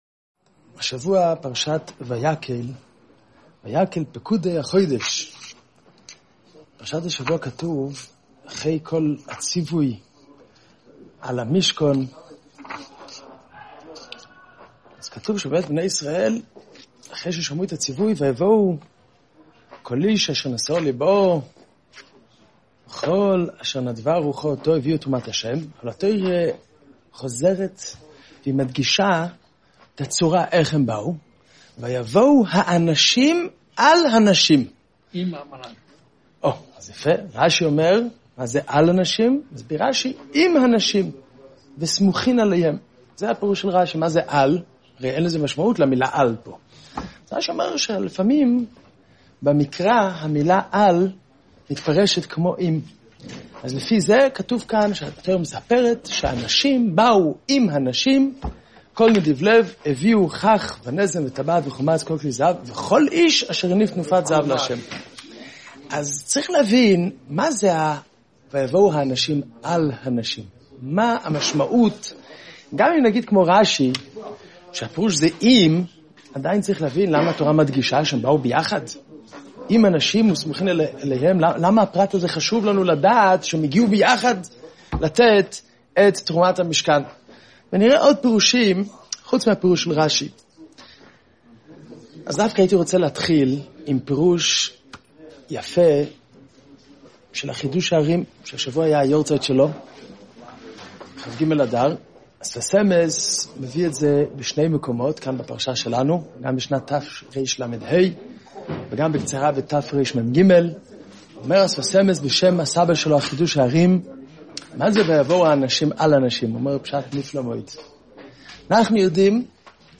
שיעור ויקהל פקודי החודש תשע”ז